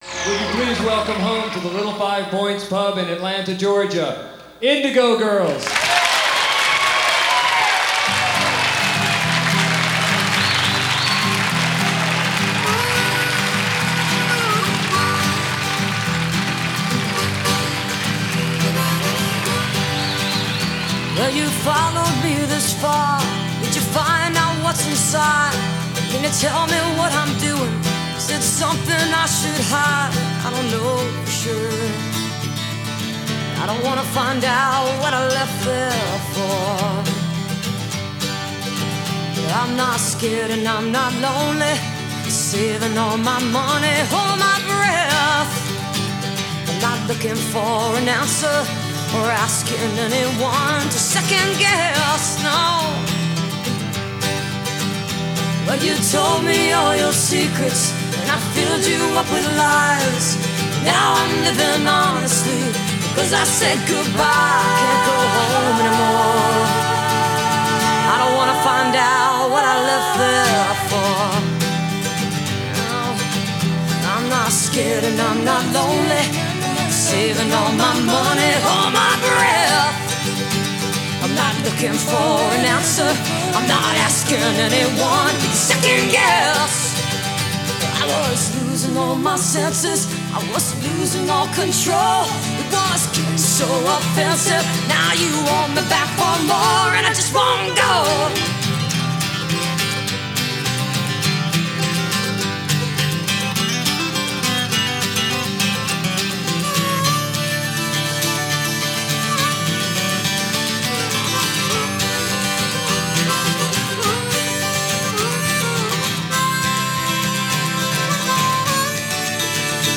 album version, with overdubbed effects